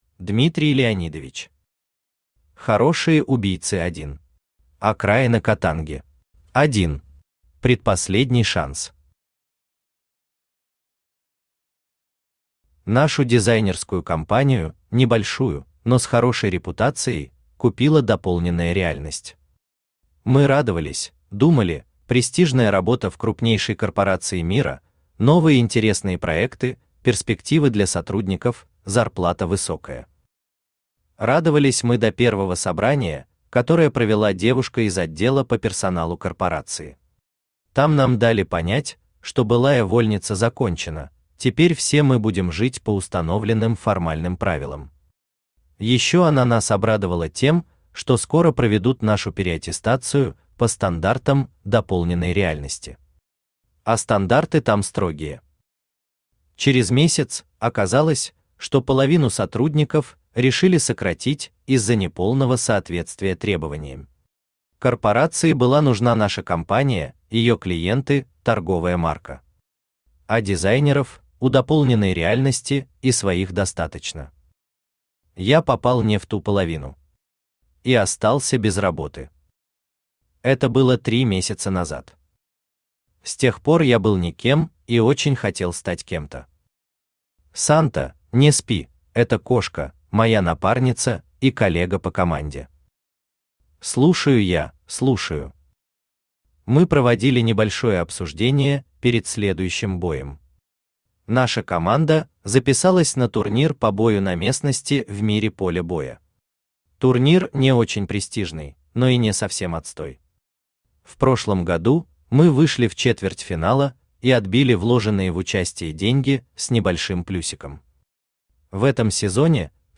Аудиокнига Хорошие убийцы | Библиотека аудиокниг
Aудиокнига Хорошие убийцы Автор Дмитрий Леонидович Читает аудиокнигу Авточтец ЛитРес.